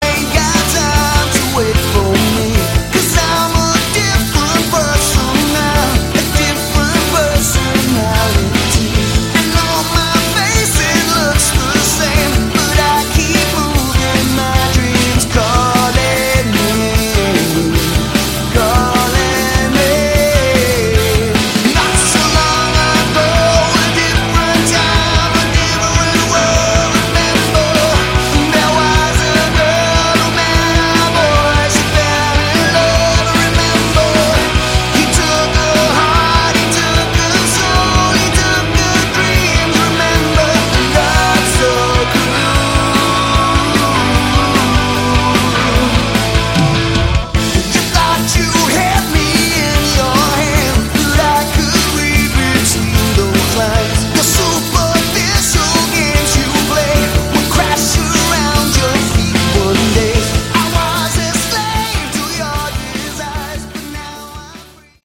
Category: Hard Rock
guitars
vocals
drums
keyboards